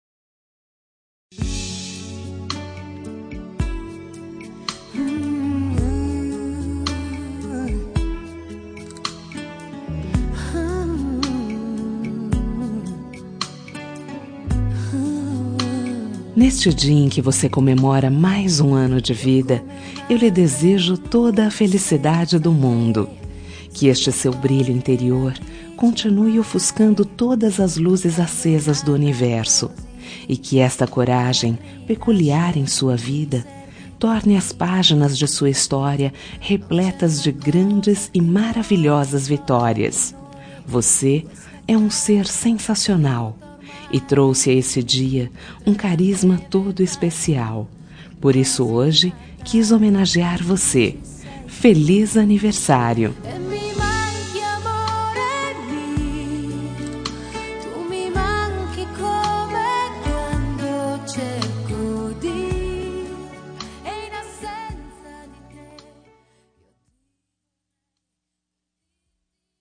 Aniversário de Pessoa Especial – Voz Feminina – Cód: 18900